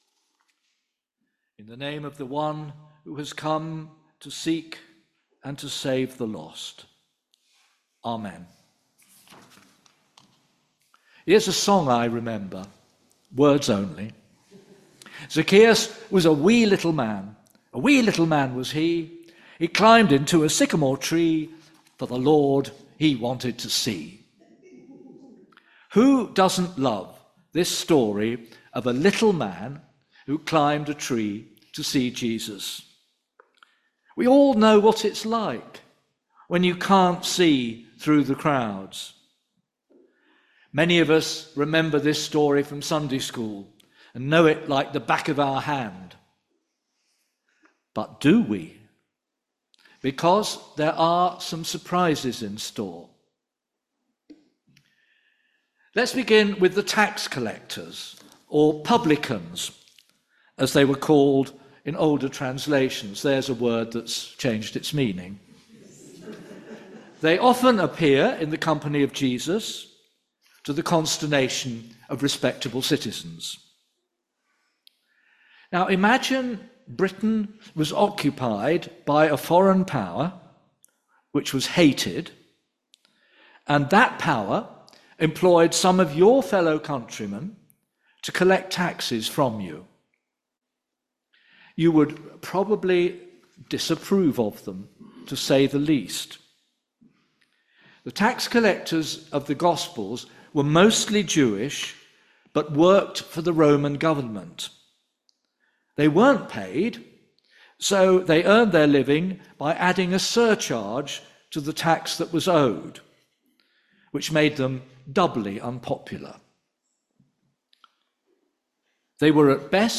Sermons – Wheatley URC